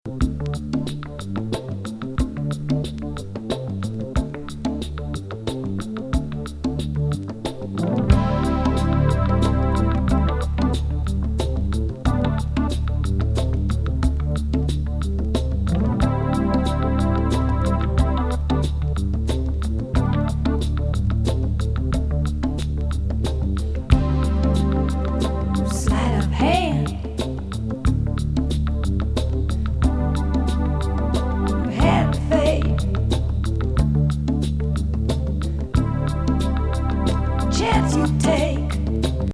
絶妙なジンワリ・シンセ感～ギターソロが堪らんＤＪユースなエレクトリック・スロー・ブギー